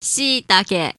The shiitake (/ʃɪˈtɑːk, ˌʃɪ-, -ki/;[1] Japanese: [ɕiꜜːtake]
Ja-Shiitake.oga.mp3